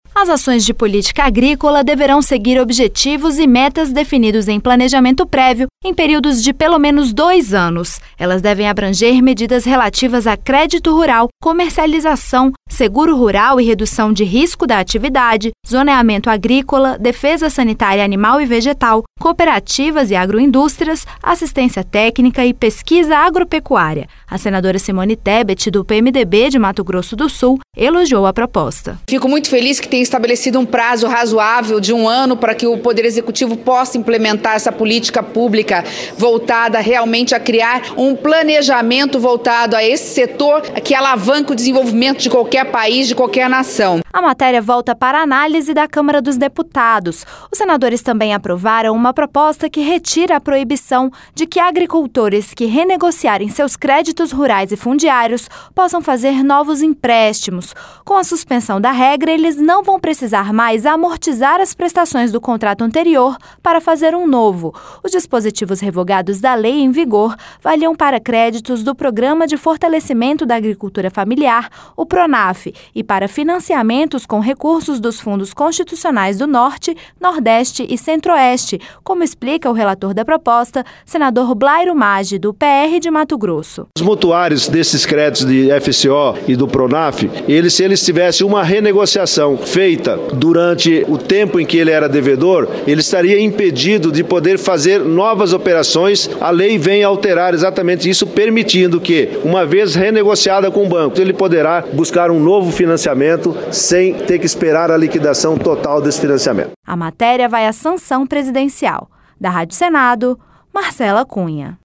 O Plenário do Senado aprovou nesta quarta-feira (27) o PLC 54/2015. que determina que o poder público implemente ações de política agrícola, em todo o território nacional. Os senadores também aprovaram a suspensão de restrições para novos empréstimos a produtores rurais já endividados (PLC 87/2015). Reportagem